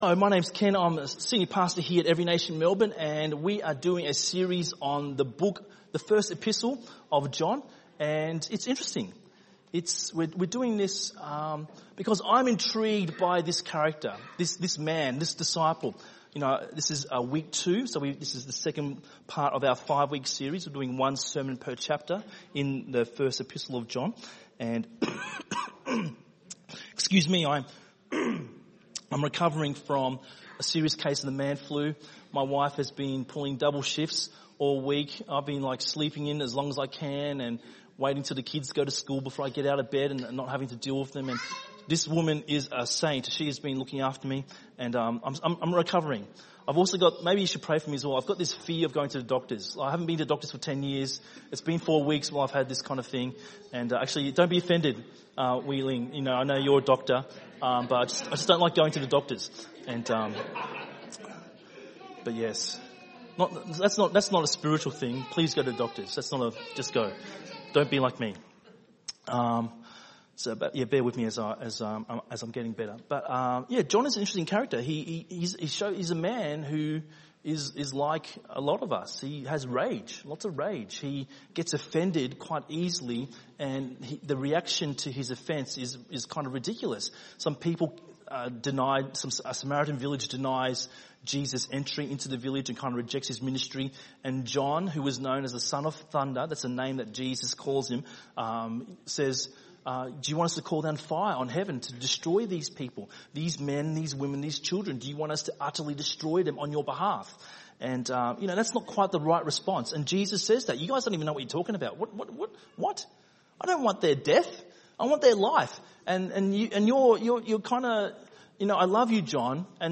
by enmelbourne | Nov 11, 2019 | ENM Sermon